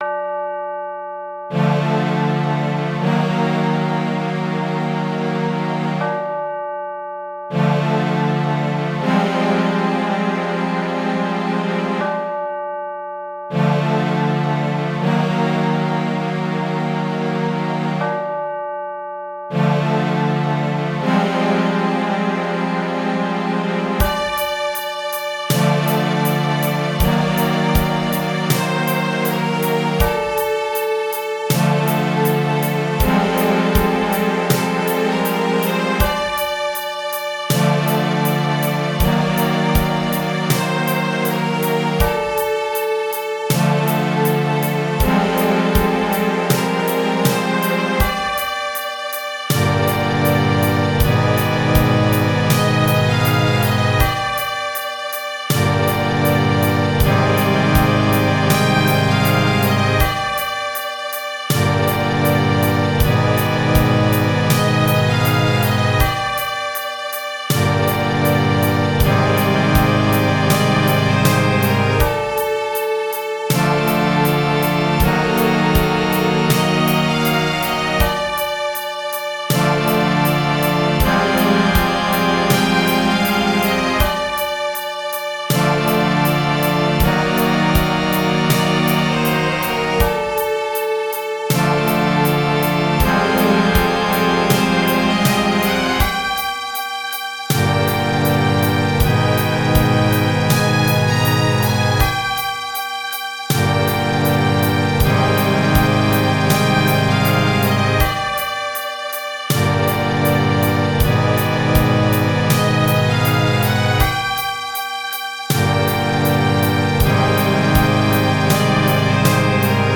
This MIDI comes from an ongoing project I'm working on for Doom: The Ultimate MIDI Pack.
I tried to make a shorter track that has a hopeless tone to it, as if the hero has already lost. This would fit best for darker levels late in a game.